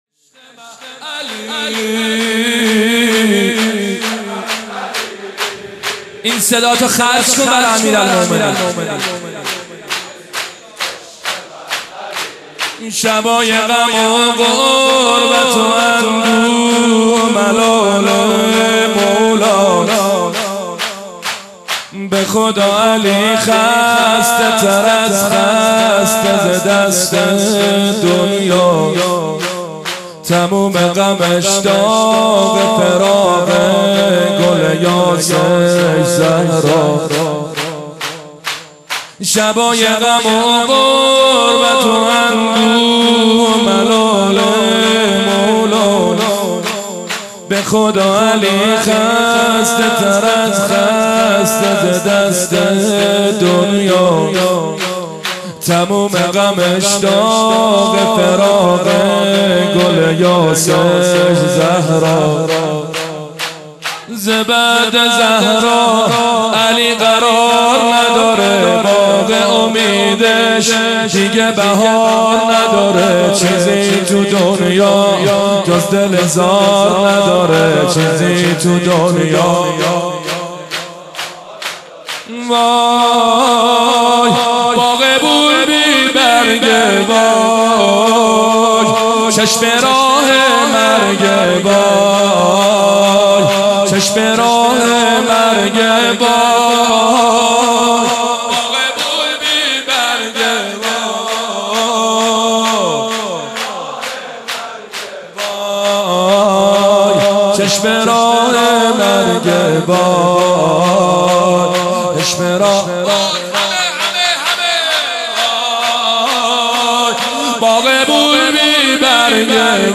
مداح
مناسبت : شب نوزدهم رمضان - شب قدر اول
قالب : زمینه